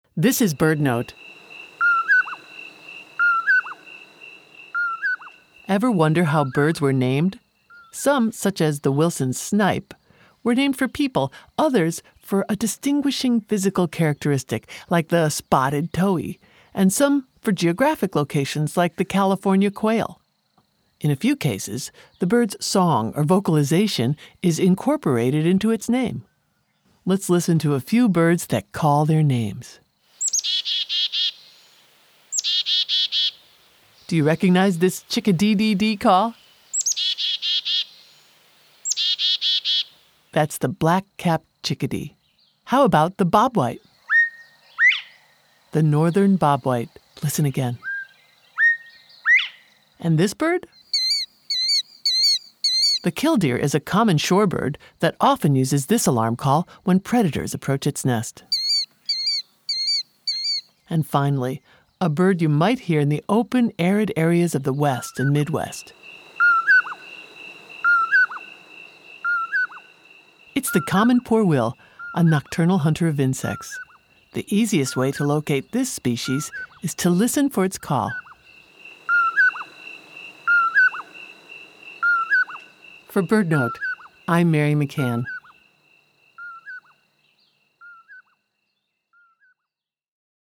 Some birds, such as the Northern Bobwhite, get their name from their songs or vocalizations: “Bobwhite! Bobwhite!” The Killdeer is another bird named for its song: “Kill-dee, kill-dee, kill-dee.” There are others. “Poorwill, poorwill, poorwill,” says this Common Poorwill. This bird is the cousin of the Whip-poor-will, another bird that calls its own name.